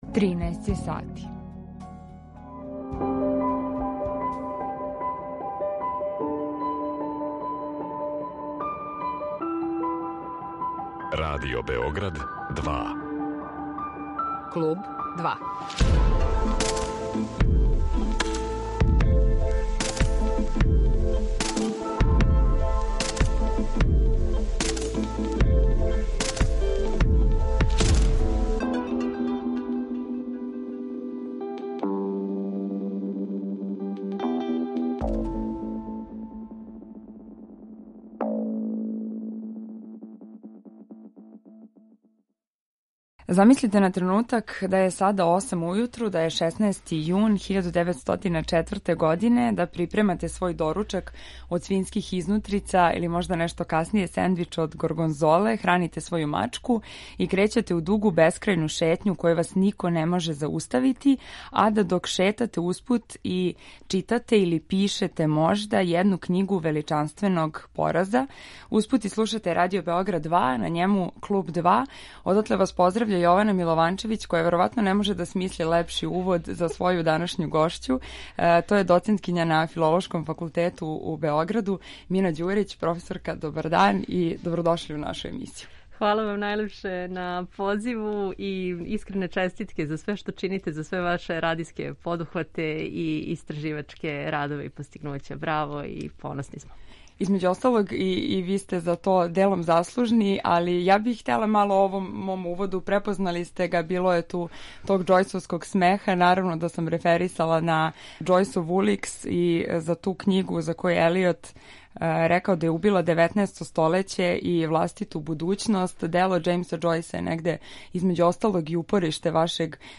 Разговор води